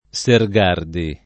[ S er g# rdi ]